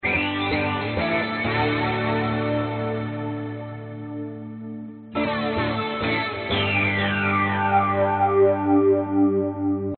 四和弦吉他曲
描述：电吉他
Tag: 100 bpm Pop Loops Guitar Electric Loops 826.98 KB wav Key : A